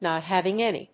アクセントnòt háving àny